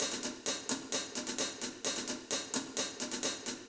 130_sabian+verb_HH_1.wav